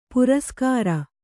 ♪ puraskāra